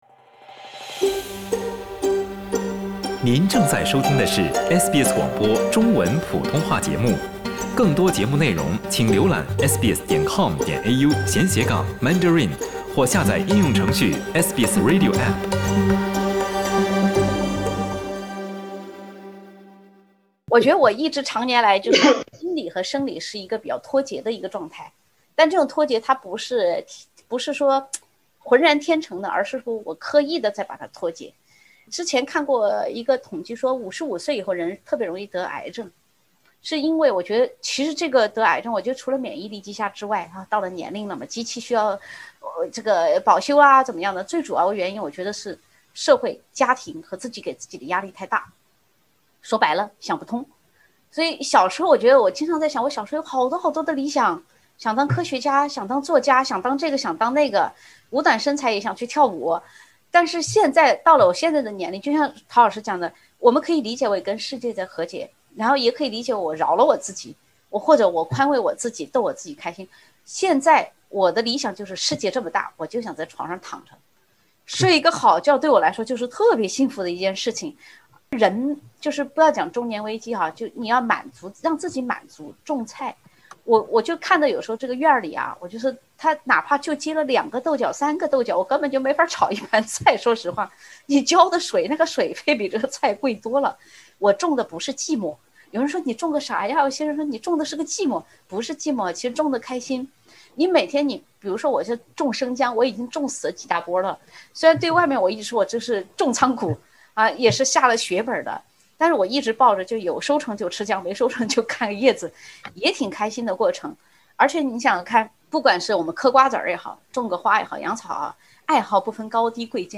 两位学者既有实践也有对策。